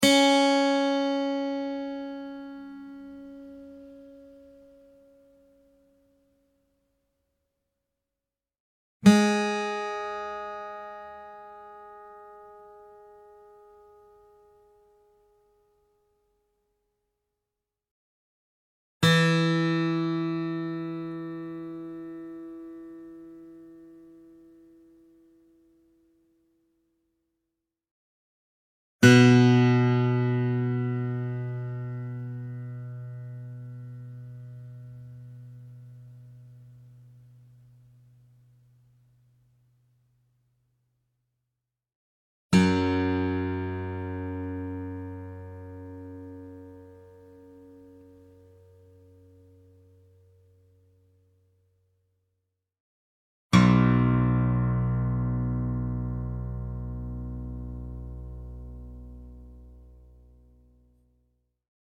Real acoustic guitar sounds in Drop B Tuning
Guitar Tuning Sounds